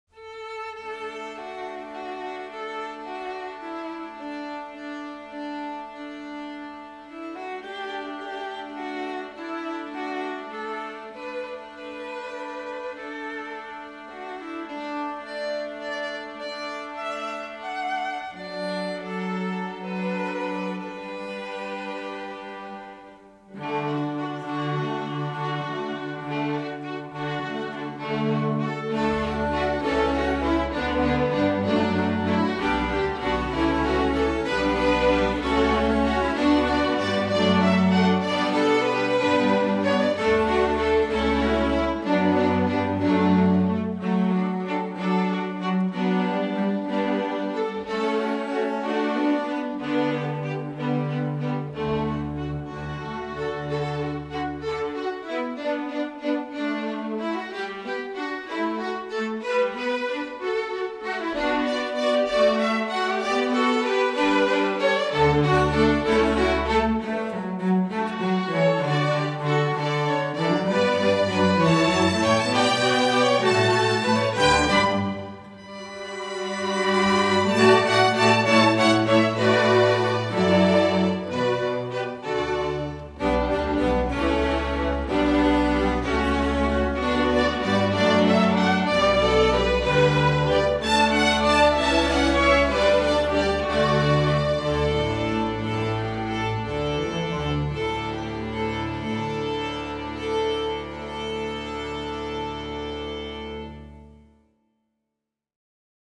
Composer: English Folk Song
Voicing: String Orchestra